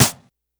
snr_26.wav